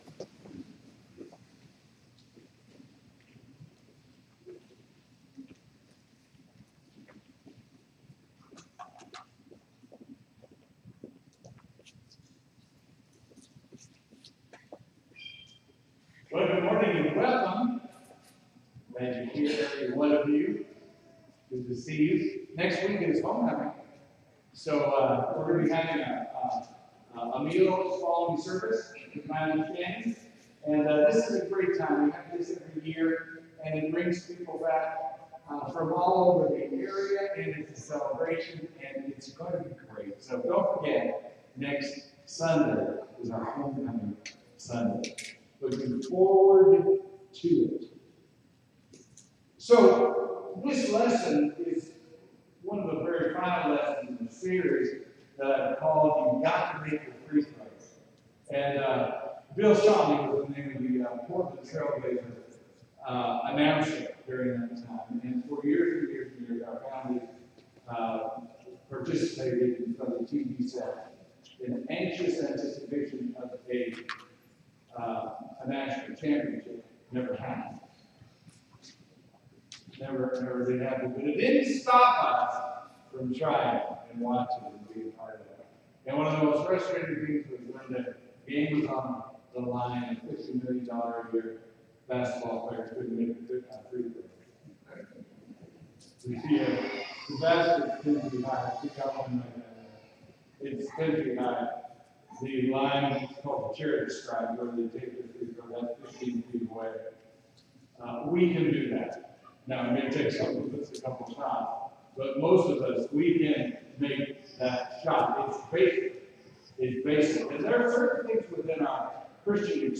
Sermon: “Come to the Table”